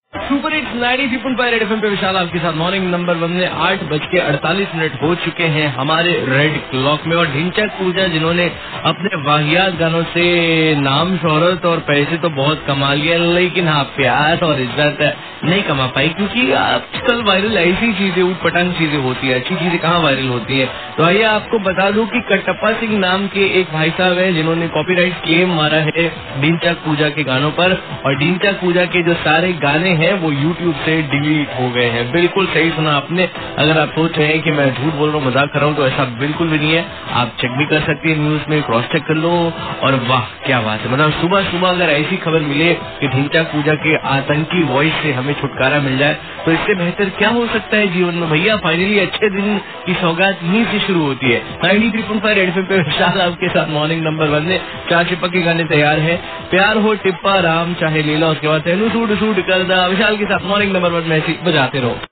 RJ TALKING ABOUT DHINCHAK POOJA